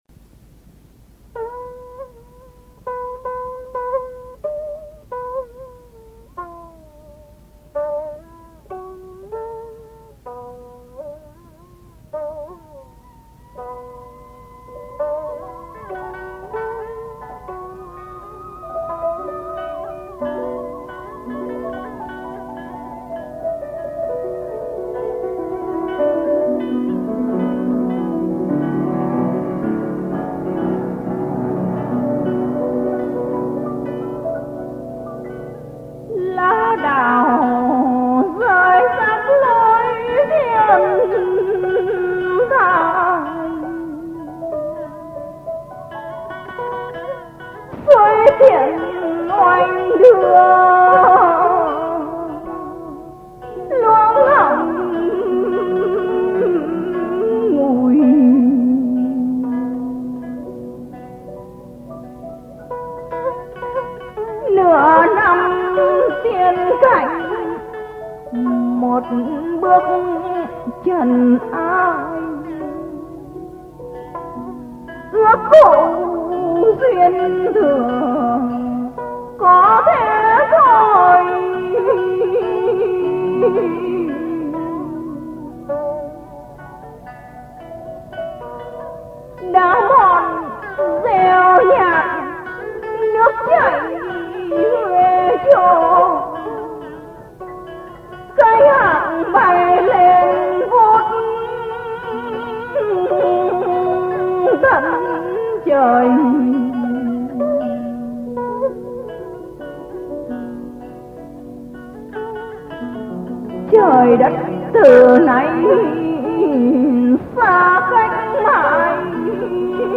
Tống biệt (thơ: Tản Đà), qua giọng ngâm Hồ Điệp